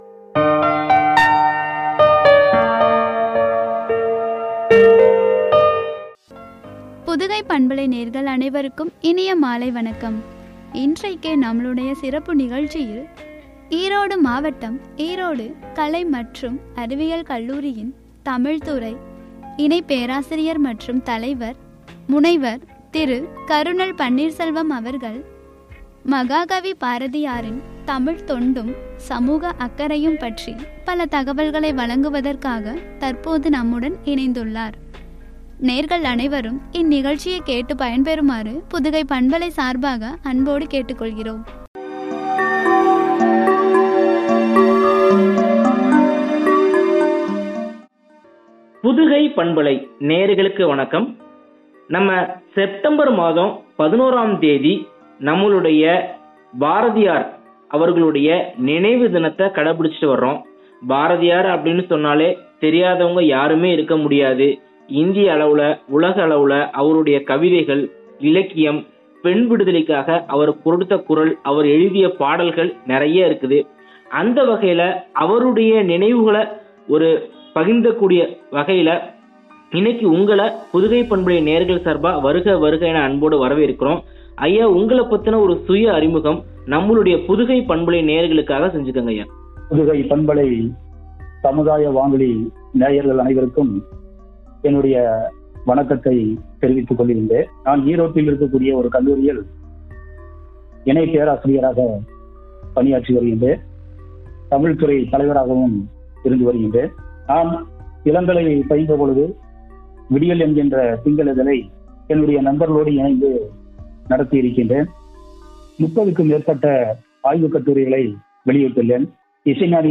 சமூக அக்கறையும்”எனும் தலைப்பில் வழங்கிய உரையாடல்.